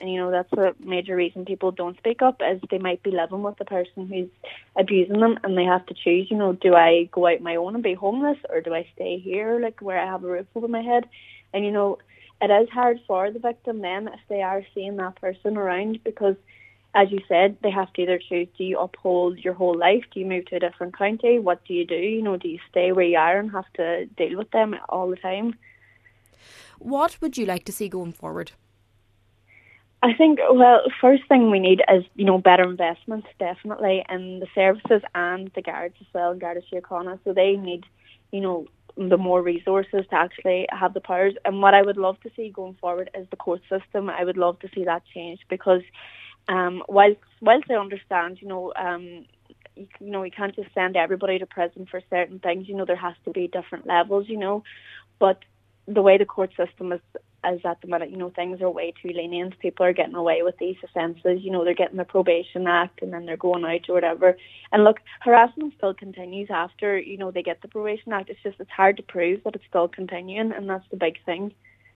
Cllr Nic Mheanman says the judicial system is not working for survivors: